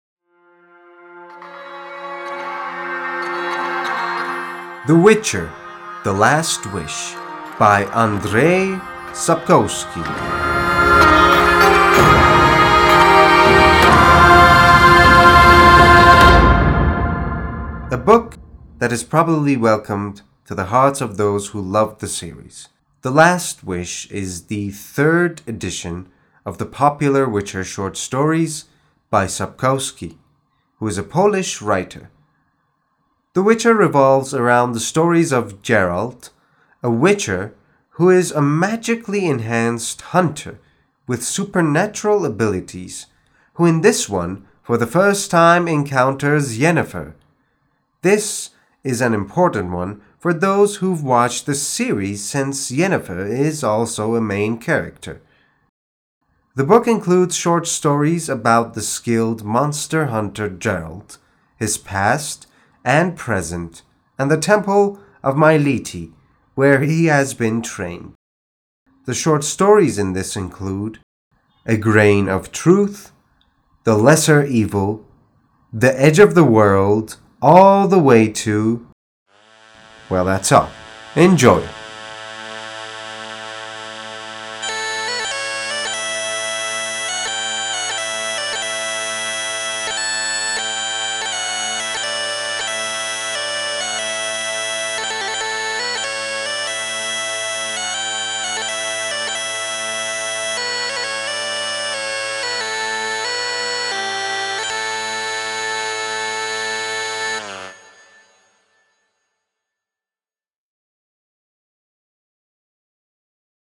معرفی صوتی کتاب The Witcher The Last Wish